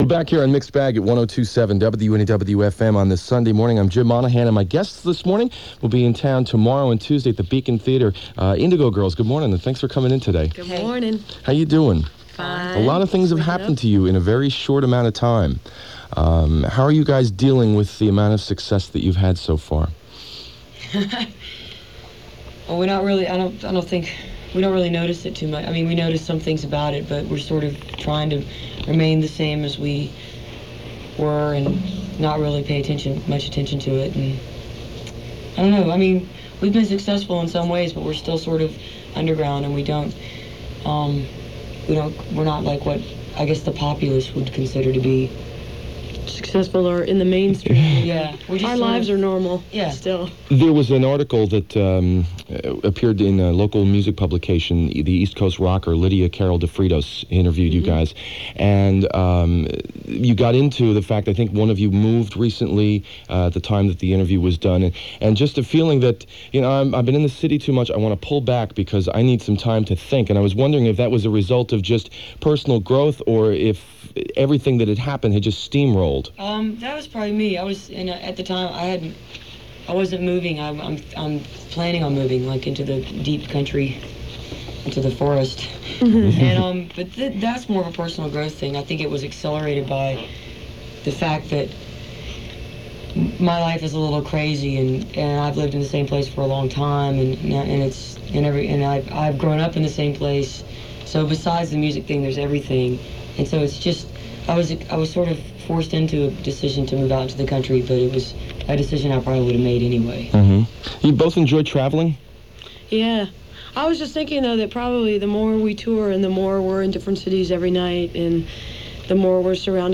(acoustic duo show)
01. interview (4:31)